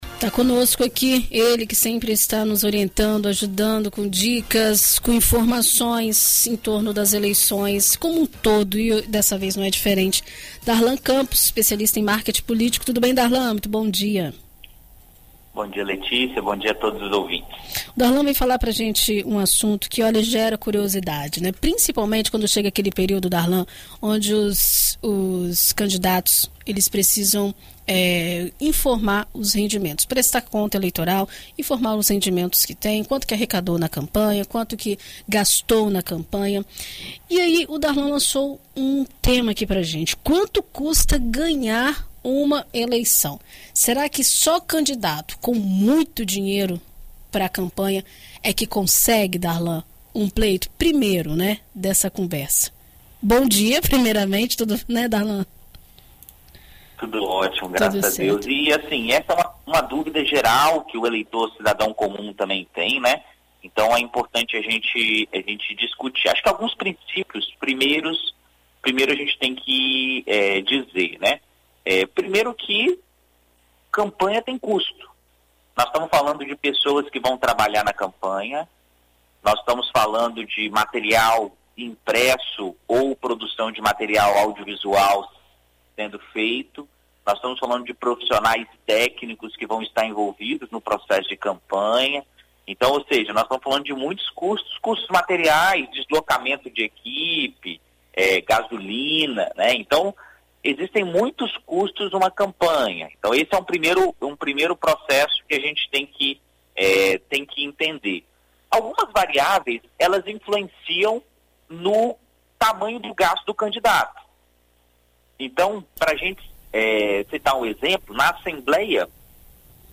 Em entrevista à BandNews FM Espírito Santo nesta sexta-feira (24)